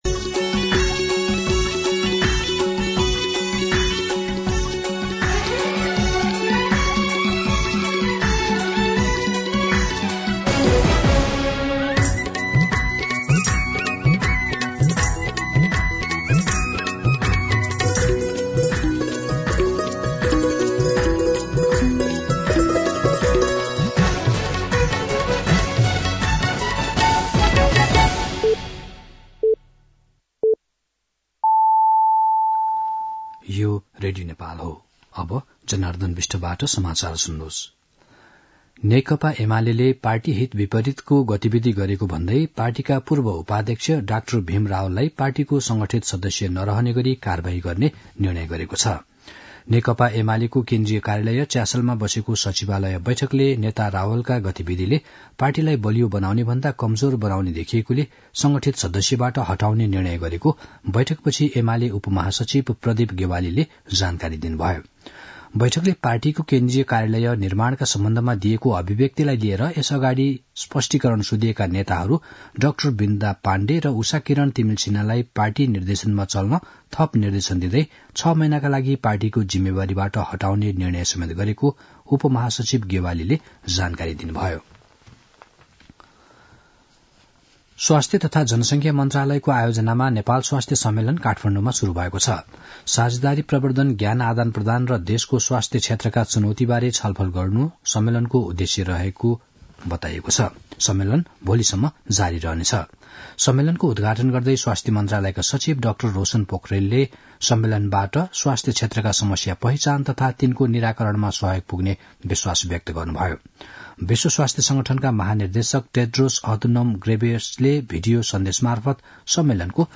मध्यान्ह १२ बजेको नेपाली समाचार : १२ पुष , २०८१
12-pm-Nepali-News-1.mp3